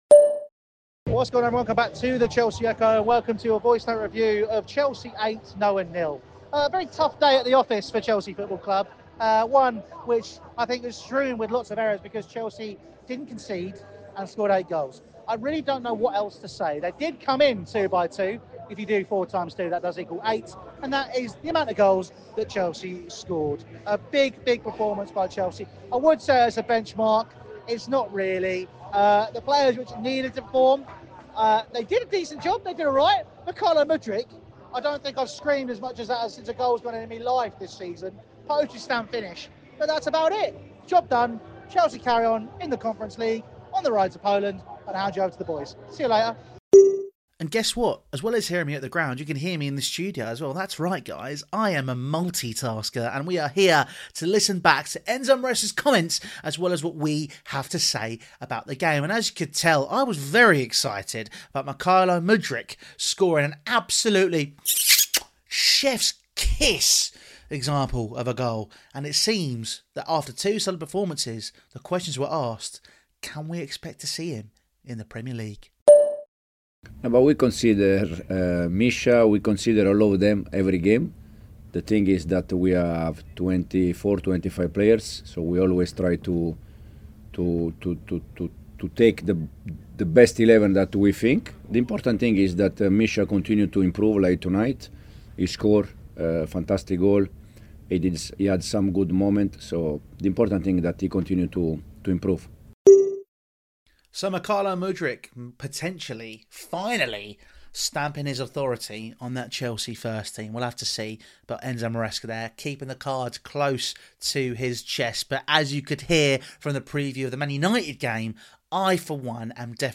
Goals come FLOODING in two by two | Chelsea 8-0 Noah | Voicenote Review